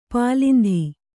♪ pālindhi